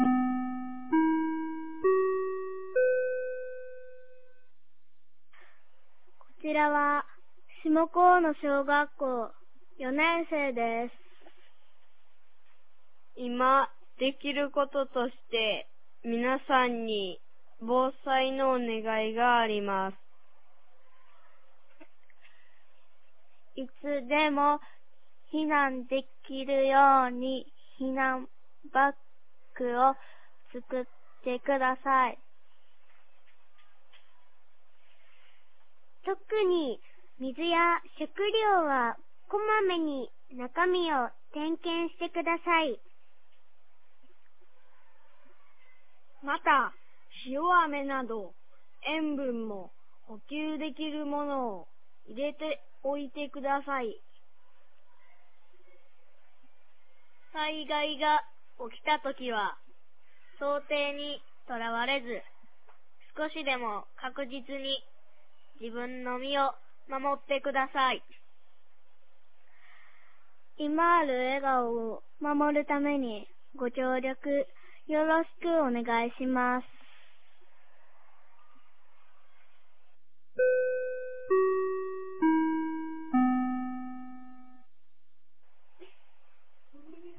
2024年01月16日 15時31分に、紀美野町より全地区へ放送がありました。
放送音声